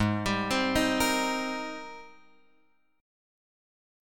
G# Major 9th